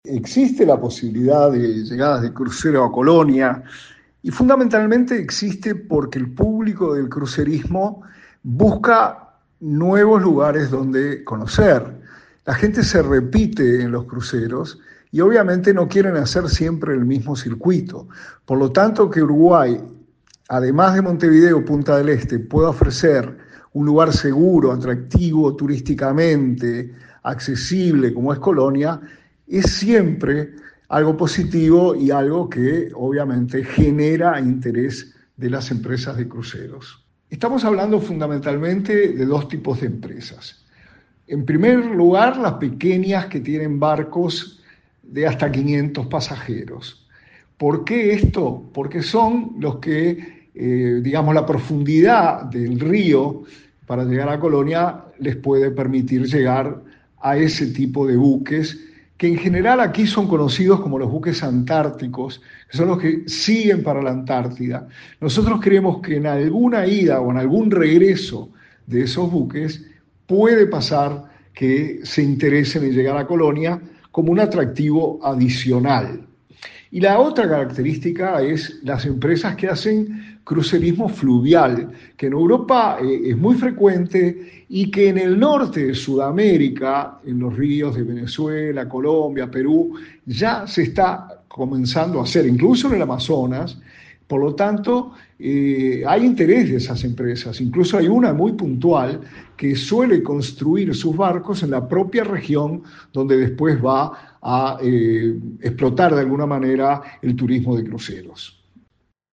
La información la dio a conocer el subsecretario de Turismo, Remo Monzeglio en diálogo con Radio del Oeste.